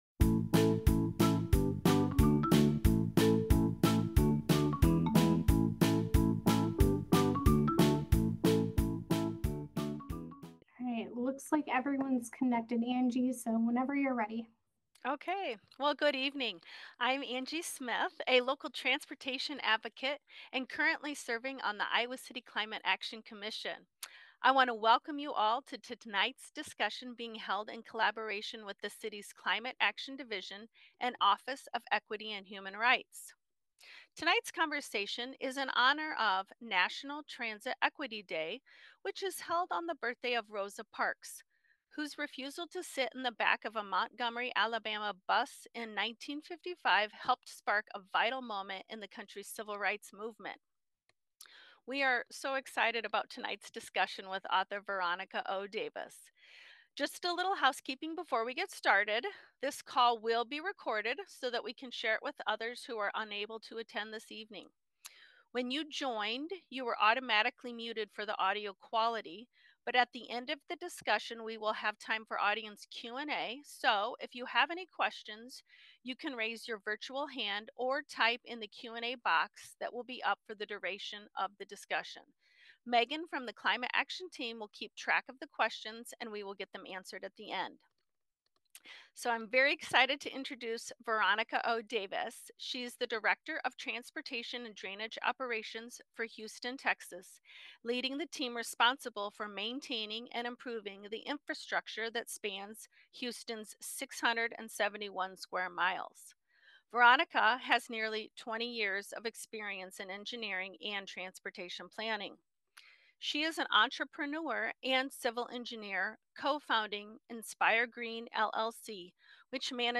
Inclusive Transportation: A Discussion
This Zoom presentation is sponsored by the Iowa City Public Library, the Iowa City Human Rights Commission, and the Iowa City Climate Action Committee.